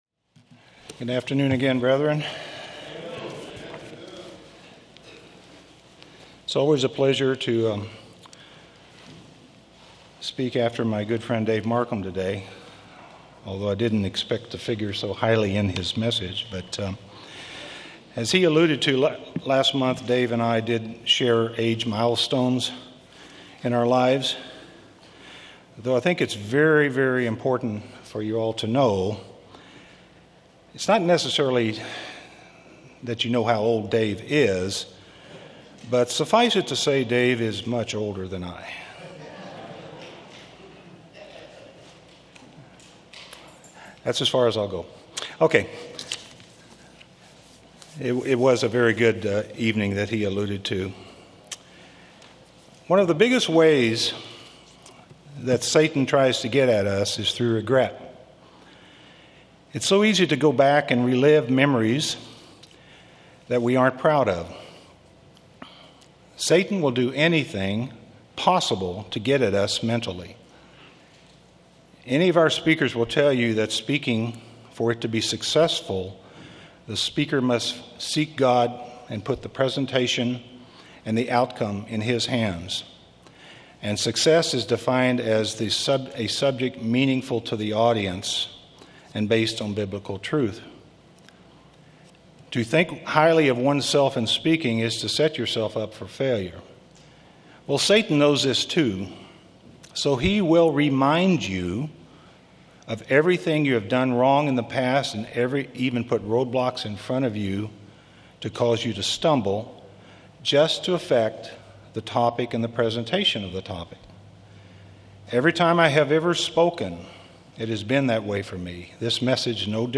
Given in Orange County, CA